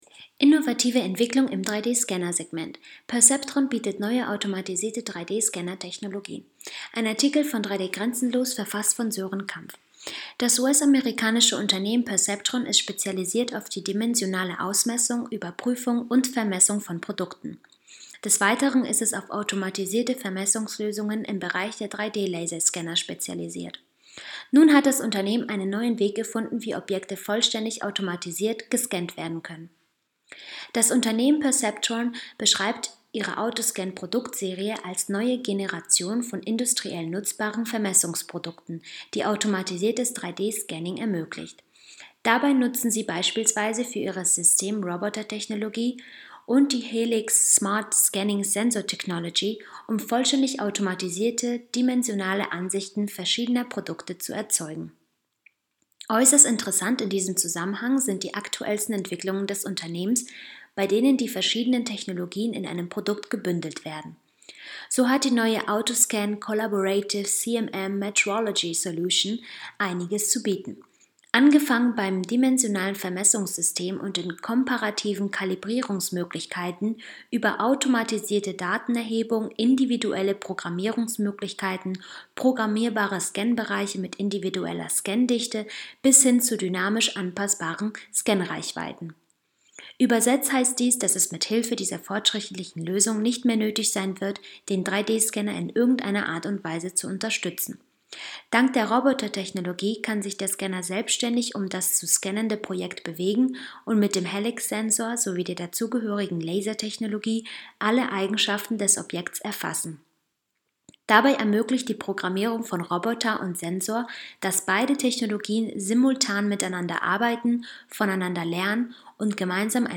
news_vorlesen_lassen_perceptron_bietet_neue_automatisierte_3d-scanner-technologien.mp3